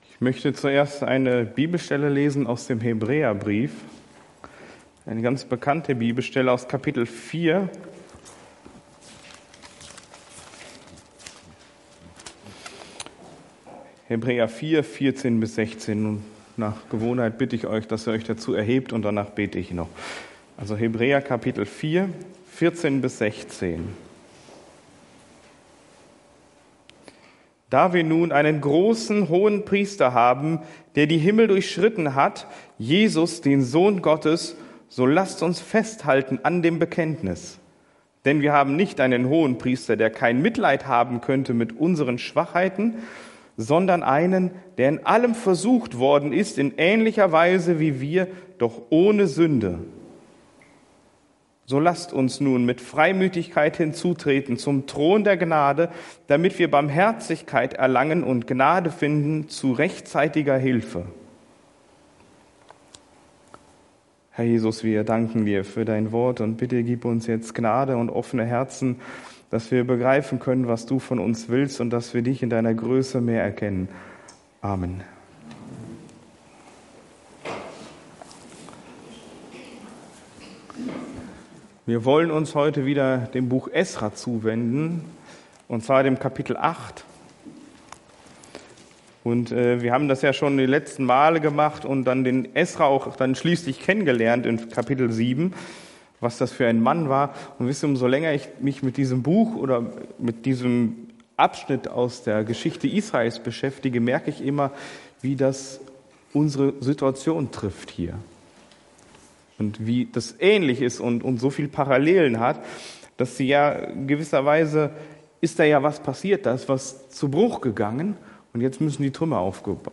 Verknüpfte Predigten